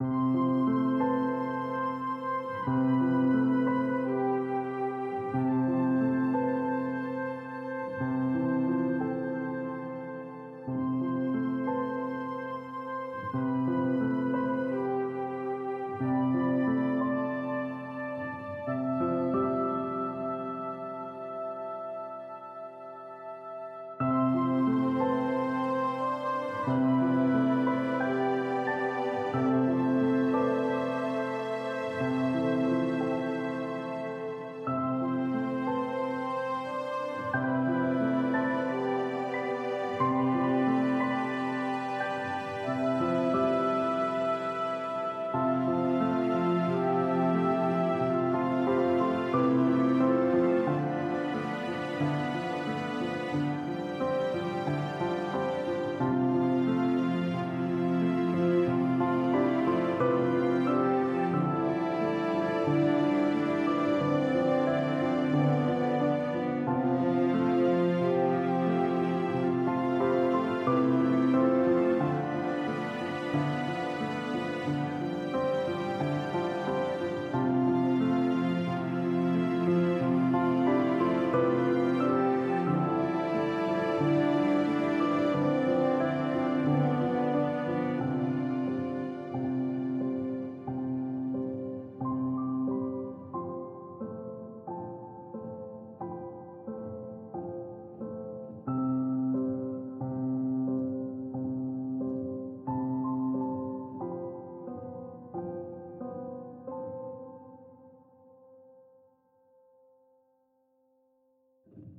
Hello Devs, A little loop able piece for a nice clam and relaxing part of your game.